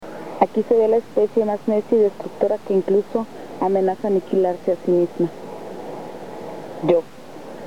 Lectura
Esta grabación fue realizada el 11 de julio del presente año en el Zoológico Regional Miguel Álvarez del Toro (ZOOMAT), que está ubicado en una reserva de aproximadamente 100 hectáreas de selva semihúmeda conocida como El Zapotal, en el lado sur oriente de Tuxtla Gutierrez, Chiapas.
Es una lectura que se encuentra dentro de una vitrina donde los visitantes se reflejan y muchas veces reflexionan o simplemente se rien.